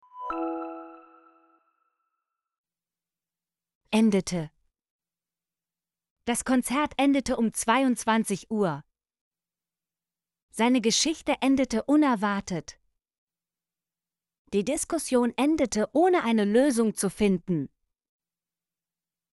endete - Example Sentences & Pronunciation, German Frequency List